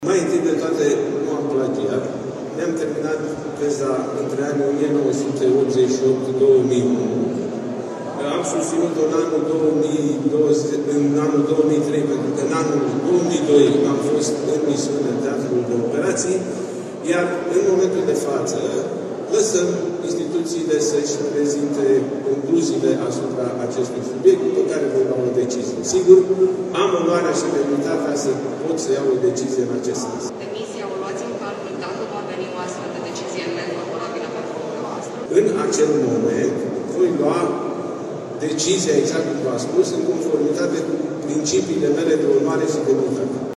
Întrebat de jurnaliști, după Congresul Extraordinar al PNL, despre o eventuală demisie în cazul unei decizii de plagiat, Nicolae Ciucă a răspuns că va lua o dicizie „în conformitate cu principiile pe care le are”.